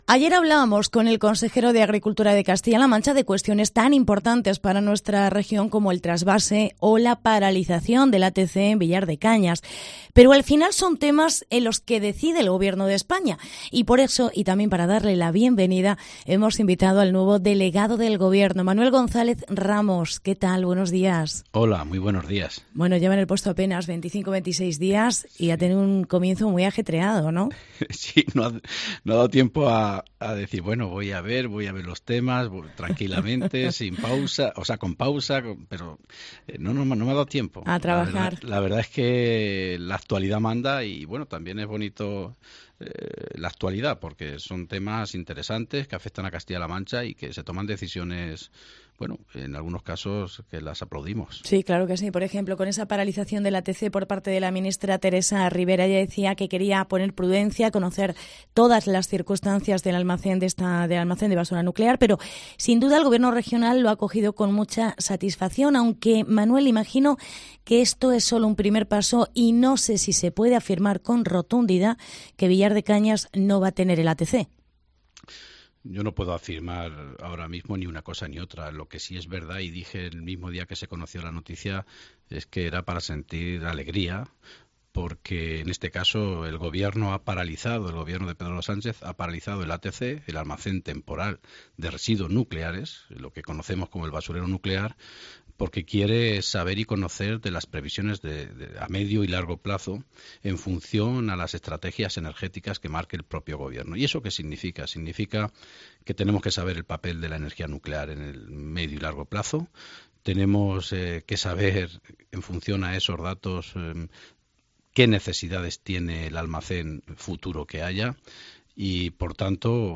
Entrevista con Manuel González. Delegado Gobierno CLM